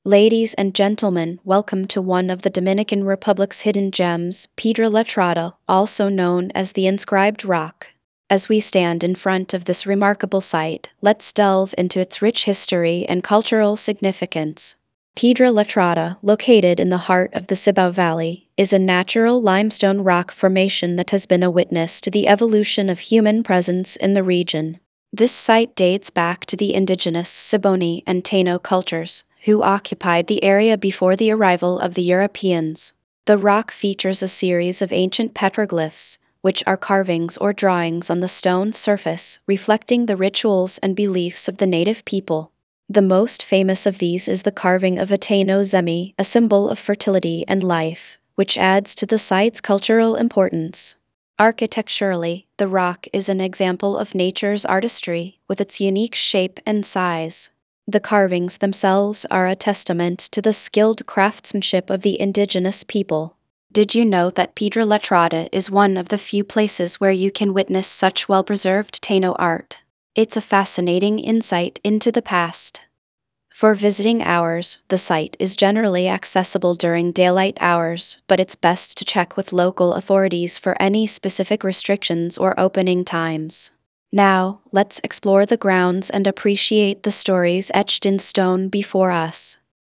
karibeo_api / tts / cache / 88b4a6b49b347d5f7ac9b945af7a75dd.wav